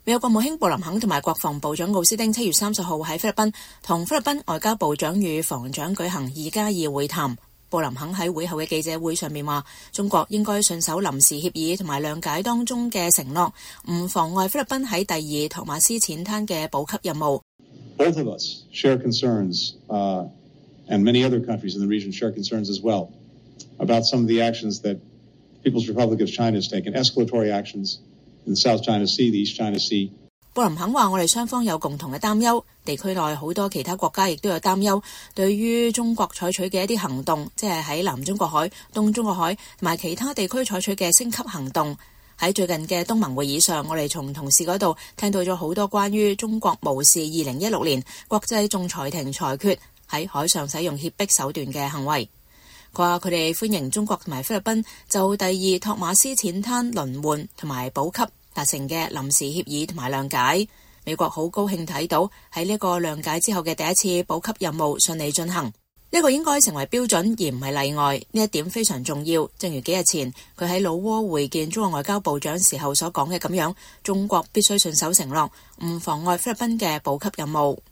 美國國務卿布林肯和國防部長奧斯汀7月30日在菲律賓和菲律賓外交部長與防長舉行2+2會談。布林肯在會後記者會上表示，中國應信守臨時協議和諒解中的承諾，不妨礙菲律賓在第二托馬斯淺灘的補給任務。